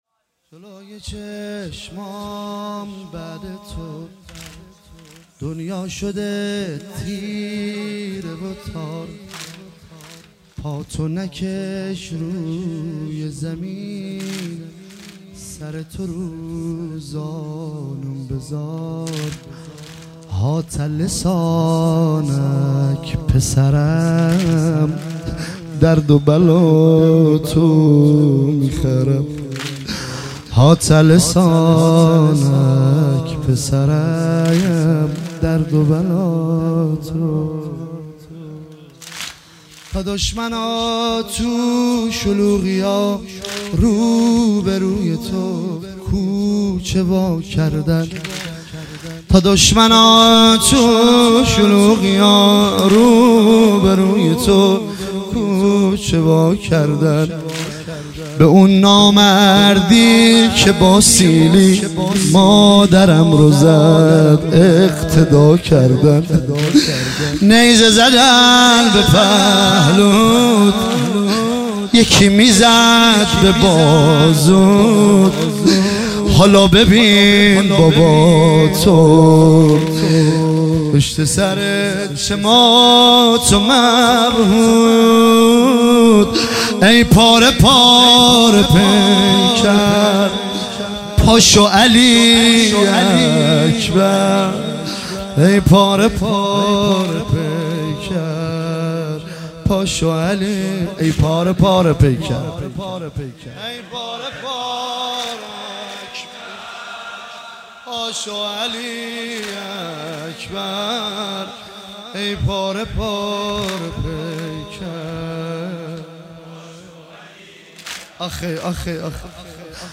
شب هشتم محرم 97 - واحد - جلوی چشمام بعد تو